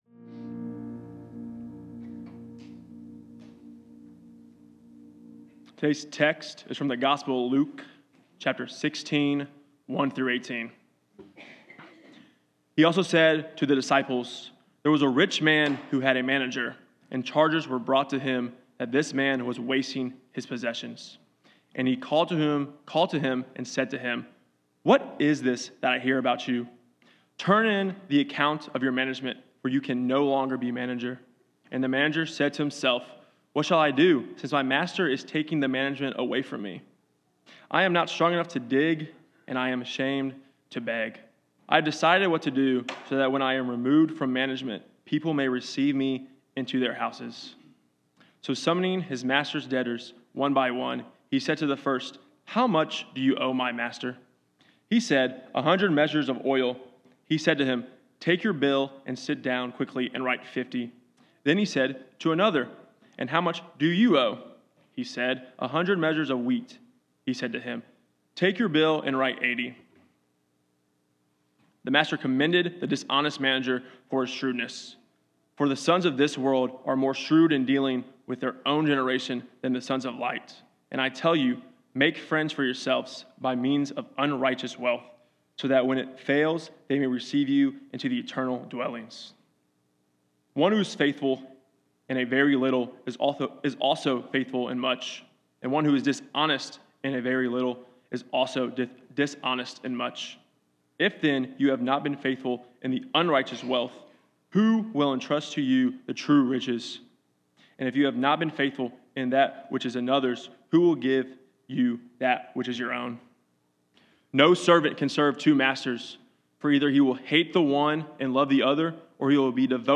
A message from the series "Psalms."